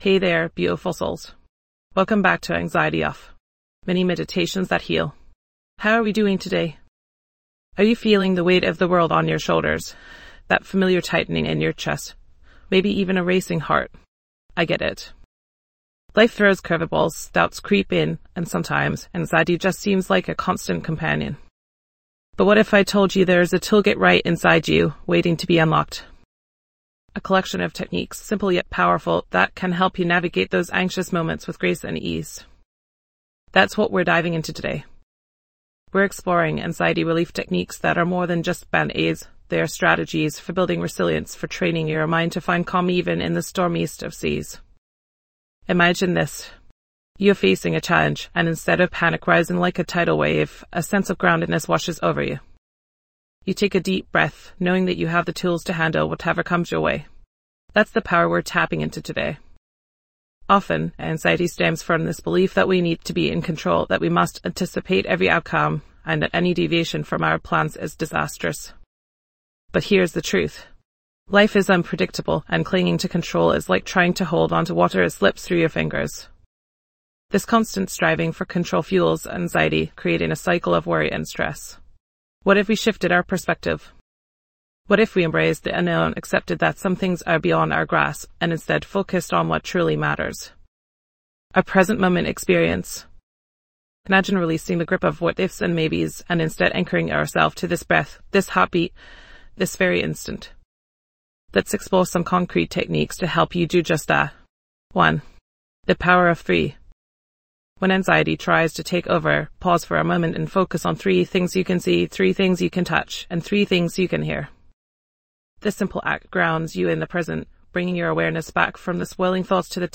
Discover effective anxiety relief techniques to help you manage stress and find inner peace in todays fast-paced world. This mini meditation episode from Anxiety Off:.